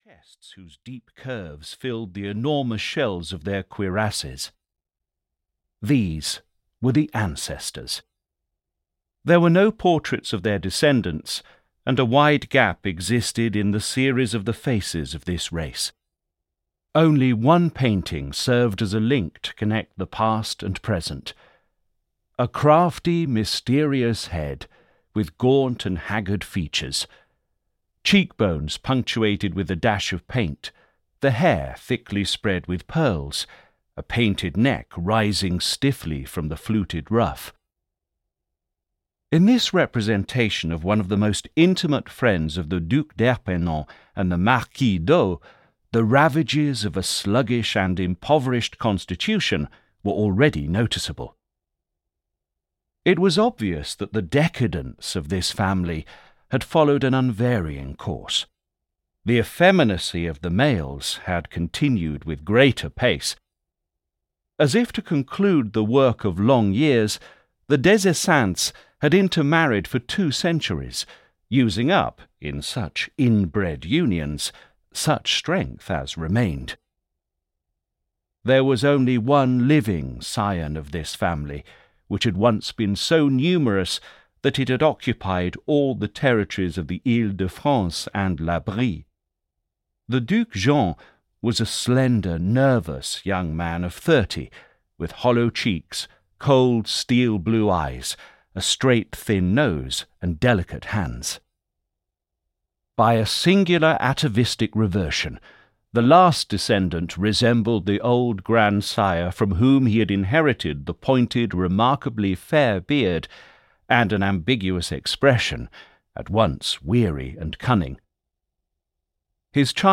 Against Nature (EN) audiokniha
Ukázka z knihy